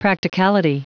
Prononciation du mot practicality en anglais (fichier audio)
Prononciation du mot : practicality